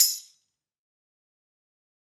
Tambourine Zion 2.wav